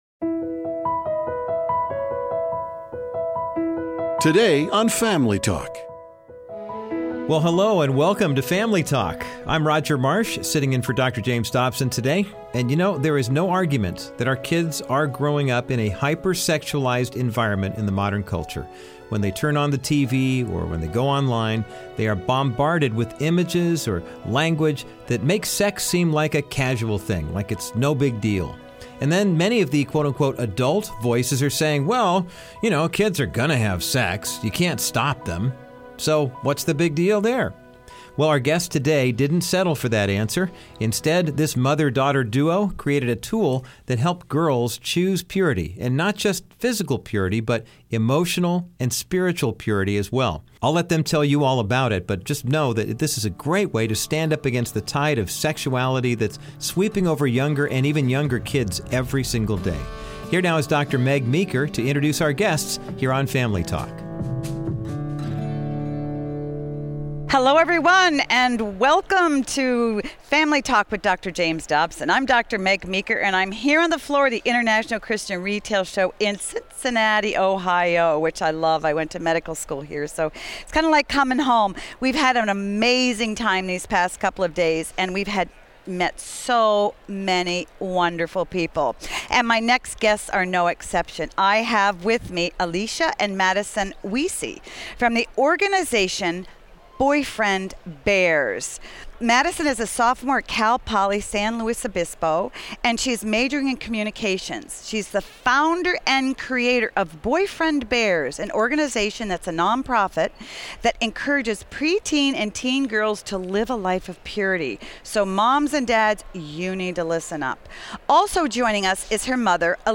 Dr. Dobson talks with a mother-daughter duo who are fighting against these misguided messages.